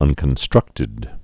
(ŭnkən-strŭktĭd)